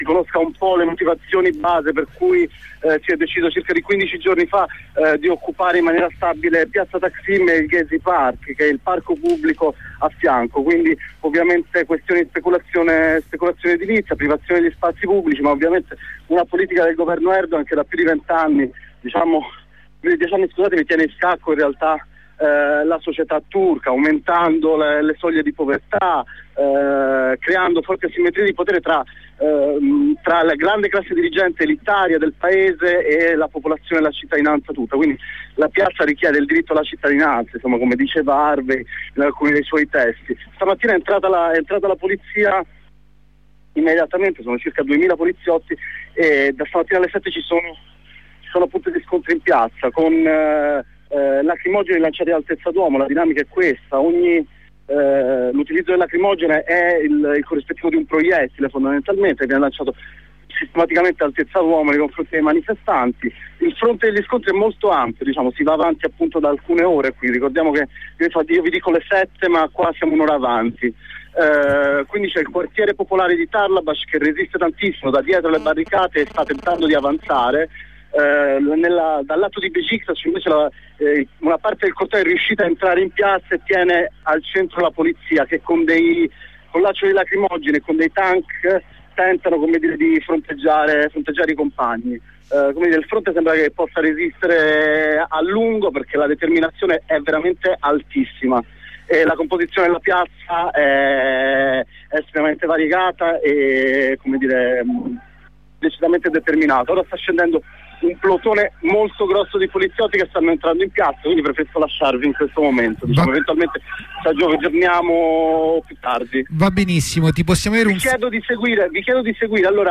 A piazza Taksim stamattina intervento durissimo da parte della polizia contro il presidio di Gezi park, le corrispondenze dalla piazza.